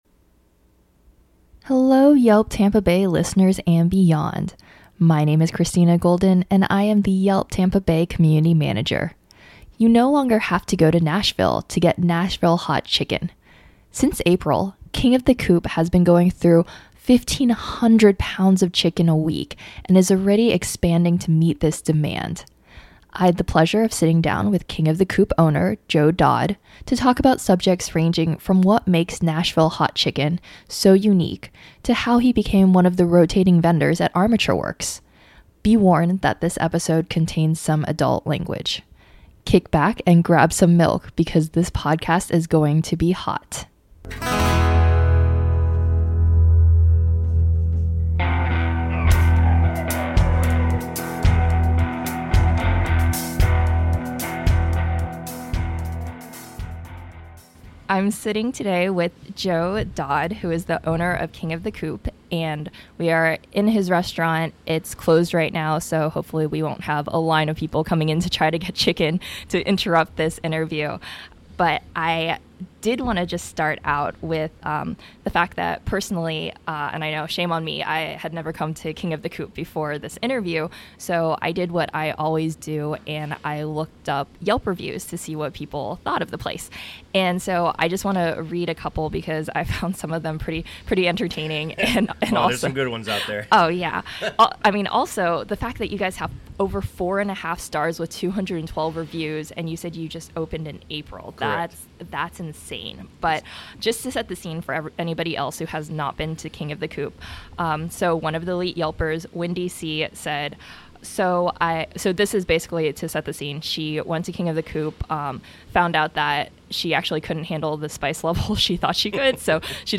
This podcast contains some adult language.